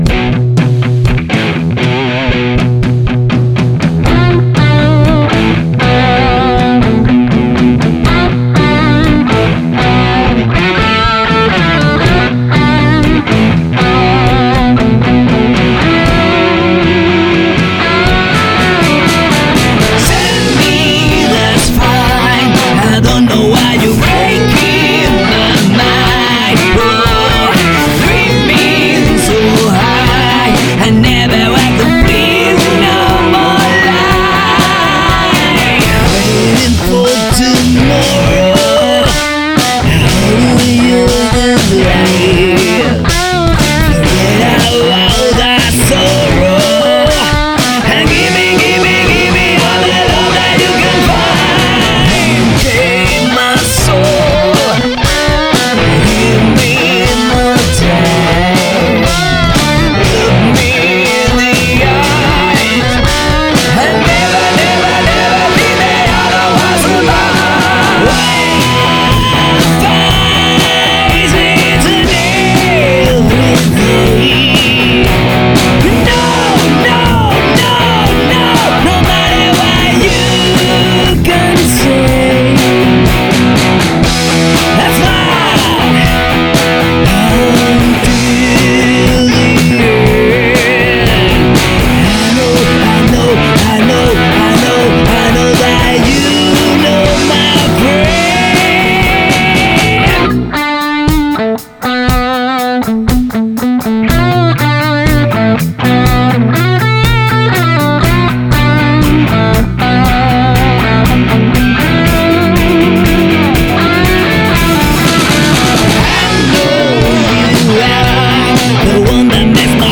batería
Bajo
Guitarras
voz